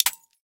pinBreak.ogg